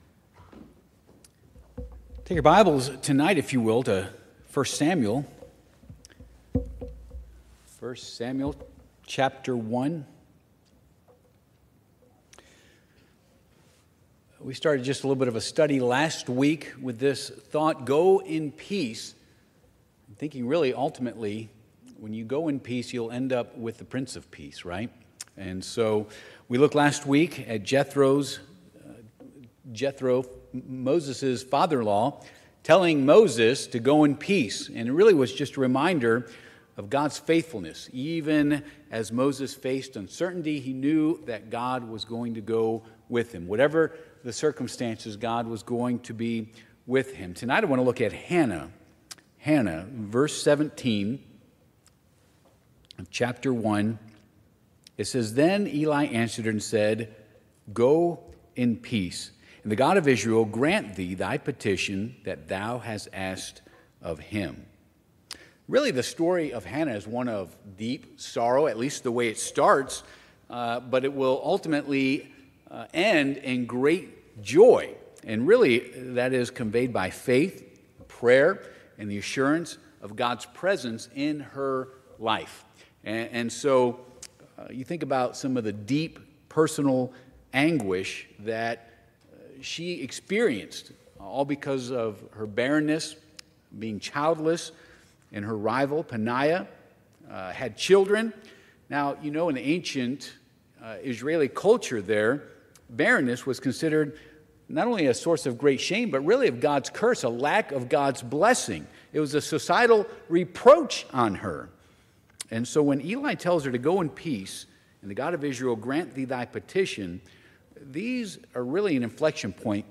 Service Type: Midweek Service